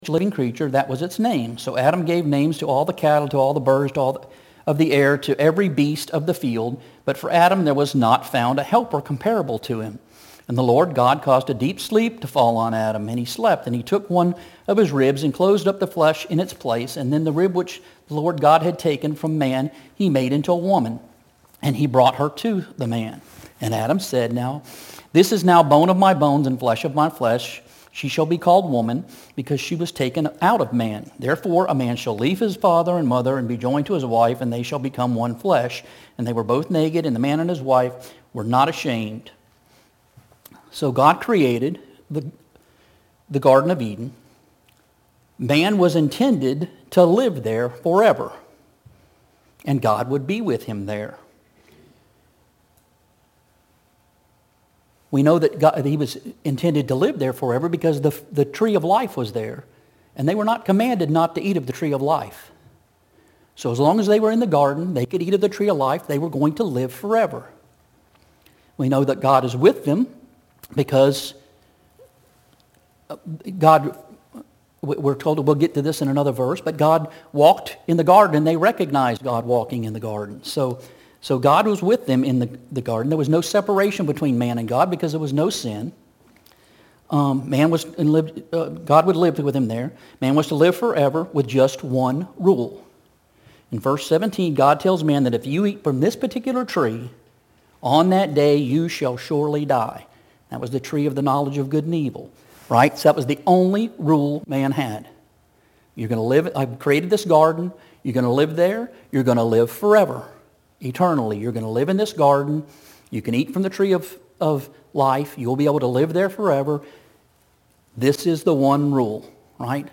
Sun AM Worship – Death